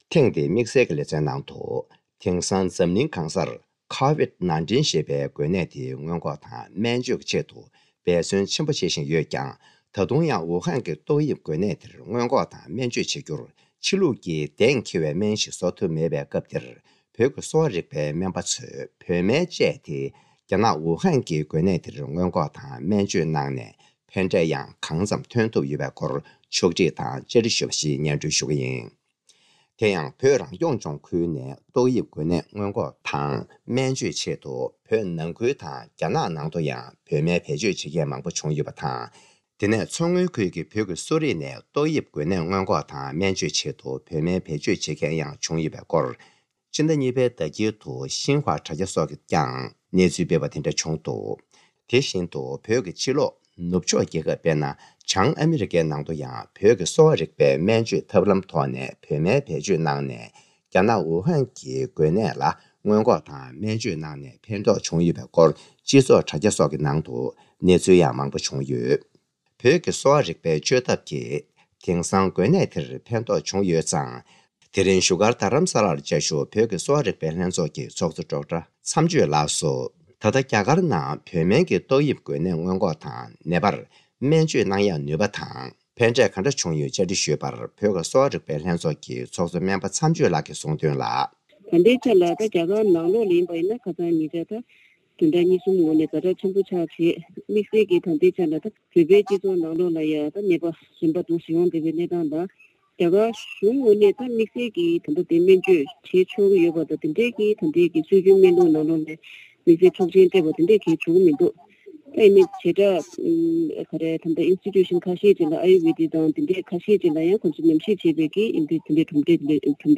སྨ་རྩིས་ཁང་དབུ་བརྙེས་ནས་ལོ་༥༠འཁོར་བའི་མཛད་སྒོའི་ཐོག་བཀའ་སློབ།
སྒྲ་ལྡན་གསར་འགྱུར། སྒྲ་ཕབ་ལེན།